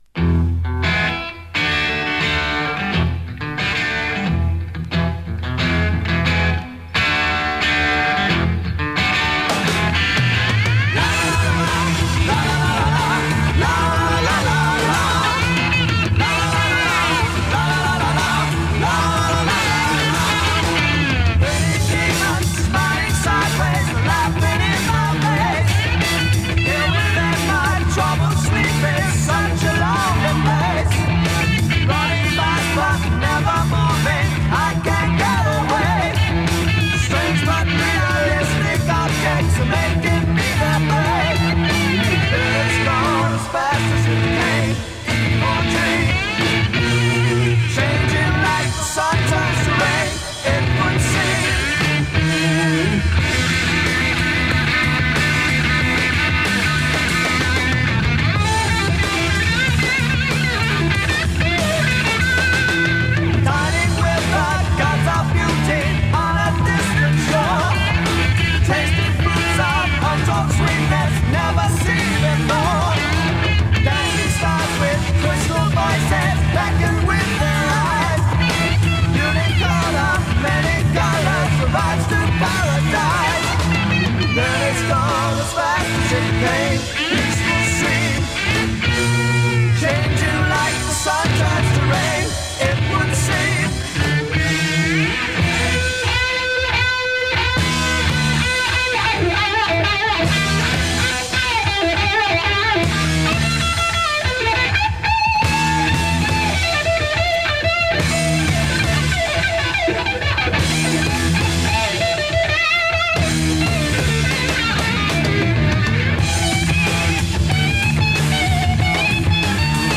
keyboard player
cross-over Hard Rock/Prog scene of the late 1960s
as it was broadcast on May 5, 1970.